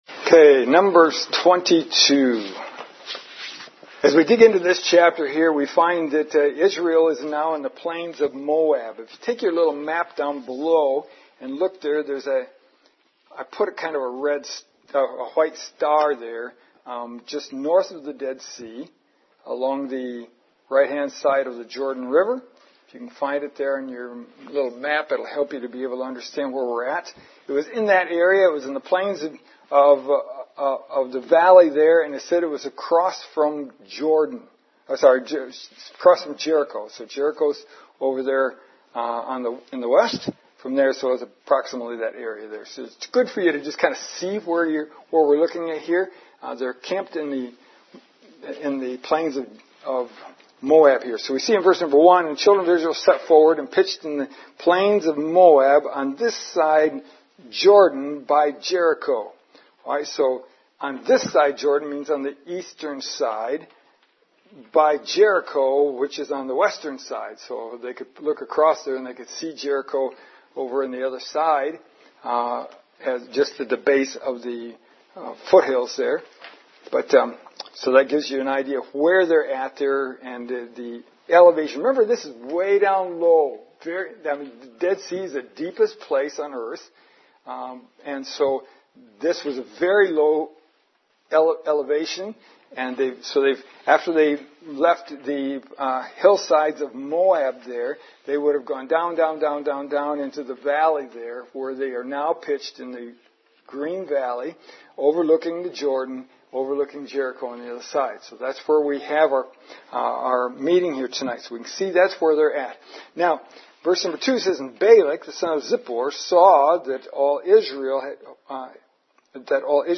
24 January 2024 Lesson: 15 Numbers 22 The Failure of Israel en route to Moab Once again, the main outline is from the Open Bible, slightly edited.